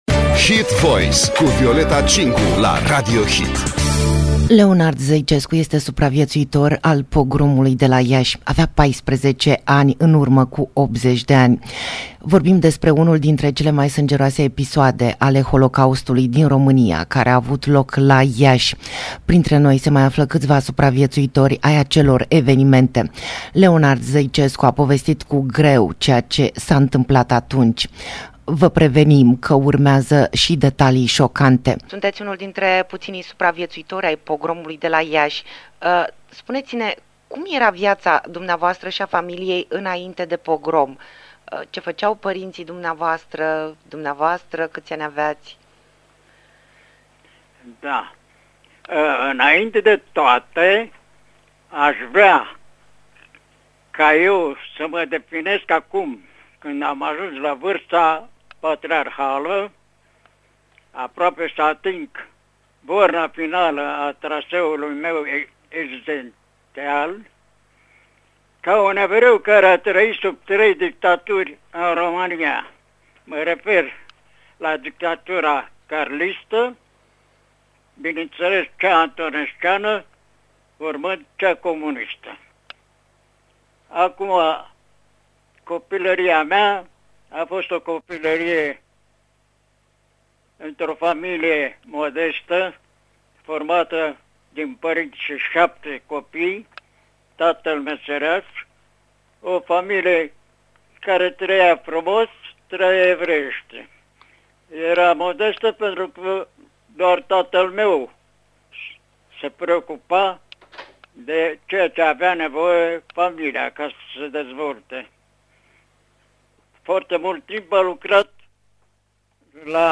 Holocaustul și Pogromul, spune supraviețuitorul tragediei de la Iași, trebuie să fie un îndemn la cunoașterea trecutului și nu încercarea, așa cum o fac unii, să ascundă sub lespedea uitării fapte care nu pot fi șterse cu buretele din trecutul României. Interviu